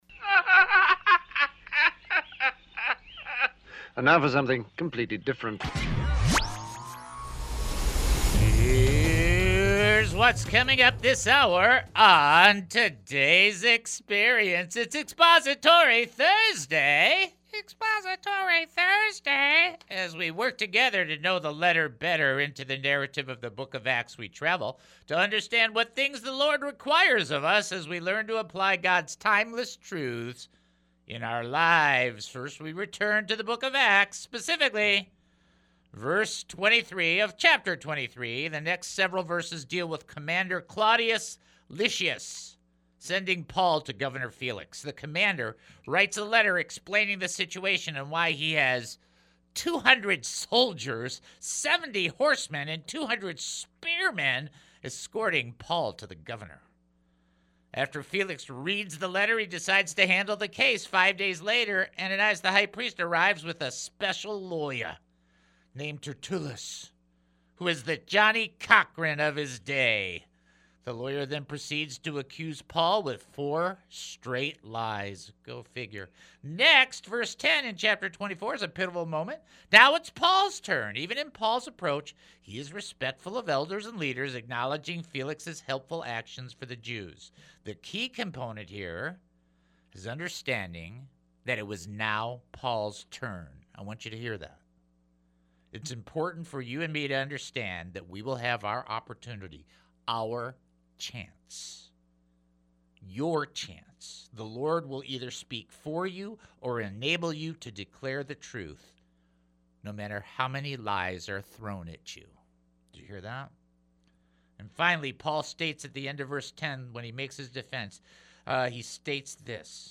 As the show concludes, we revisit an inspiring interview with Bob Hartman and Petra.